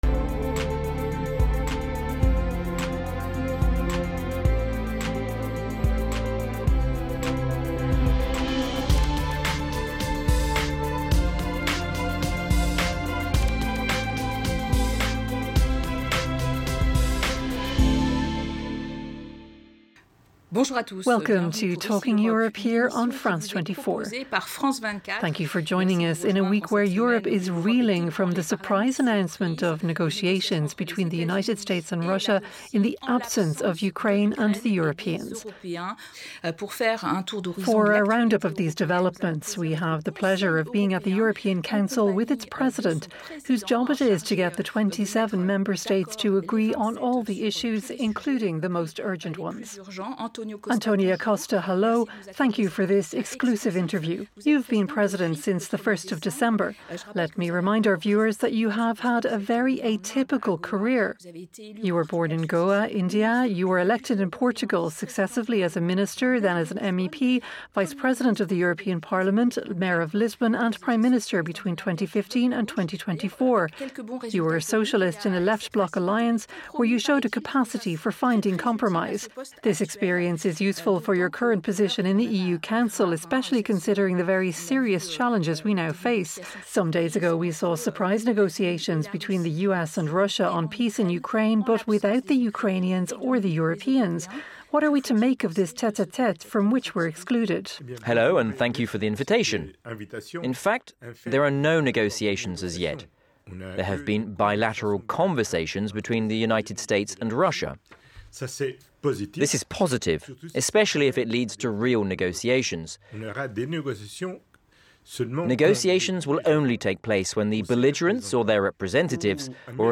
Politicians, activists and researchers debate the issues facing the EU and a 'guest of the week' offers their insight in a long-format interview that gets to the heart of the matter.